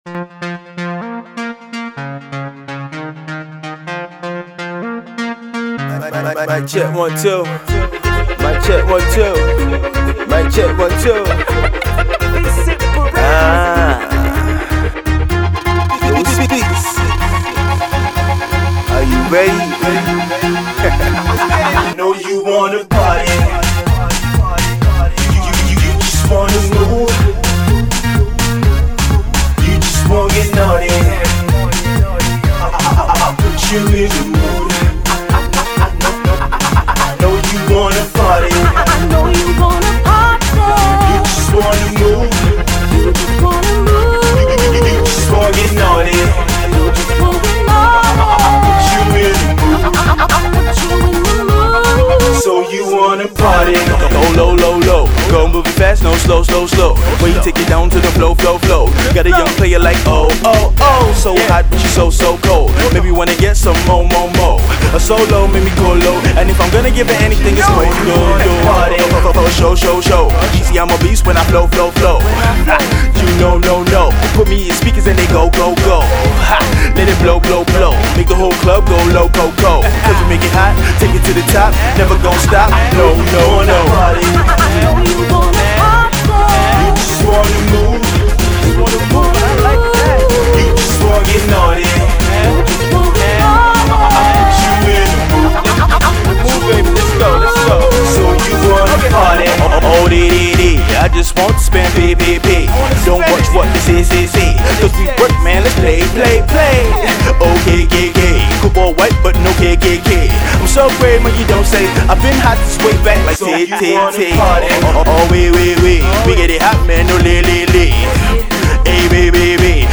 Afro-Dance